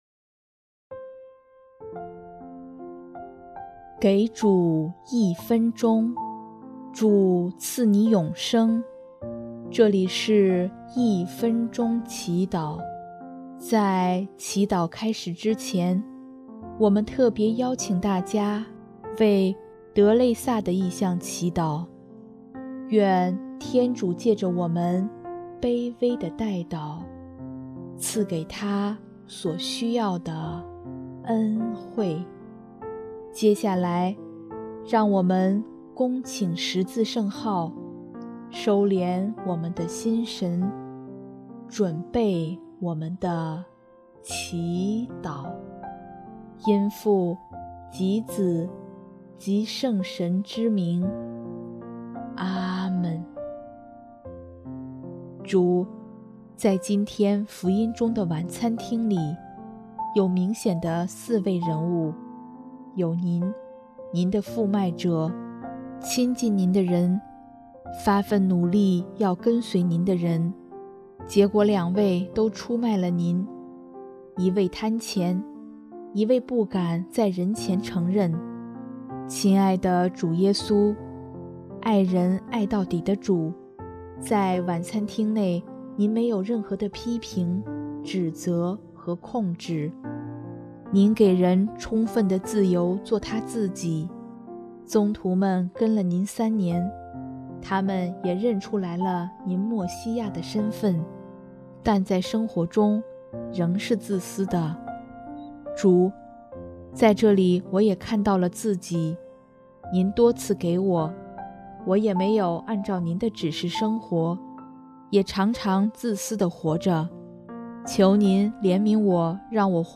【一分钟祈祷】|4月15日 耶稣让我向您一样去爱